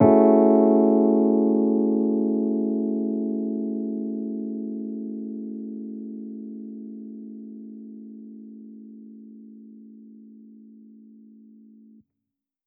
Index of /musicradar/jazz-keys-samples/Chord Hits/Electric Piano 2
JK_ElPiano2_Chord-Amaj13.wav